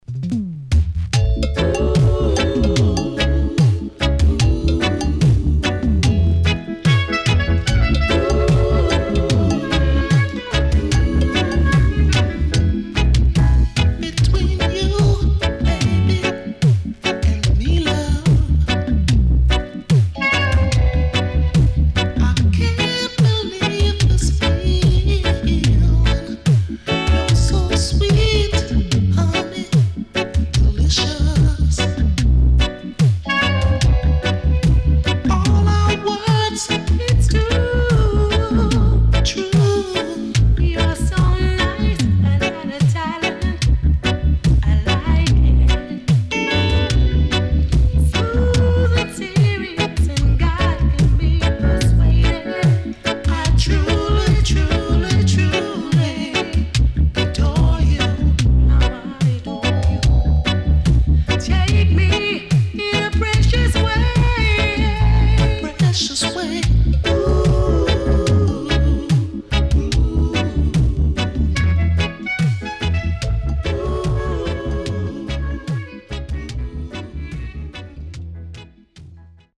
Nice Lovers Vocal.W-Side Good
SIDE A:少しチリノイズ入りますが良好です。